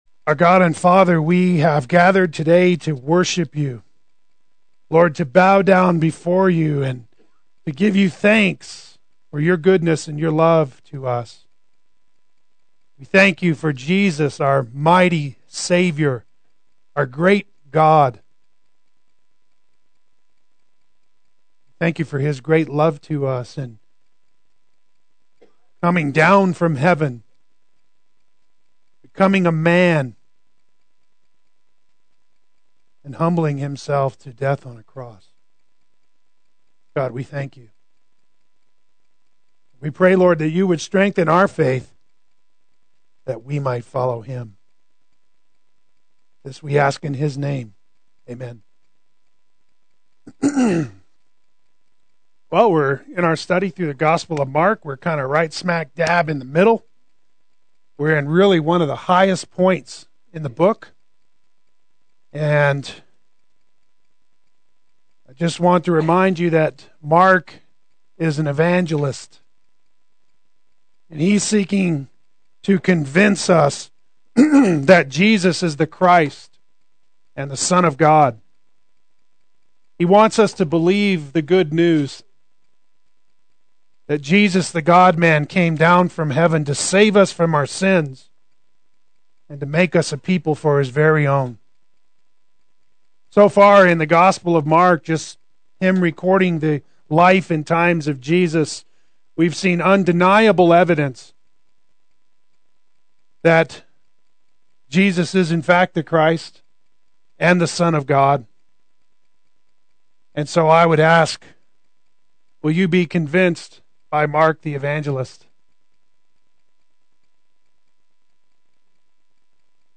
Losing Your Life for Jesus and the Gospel Adult Sunday School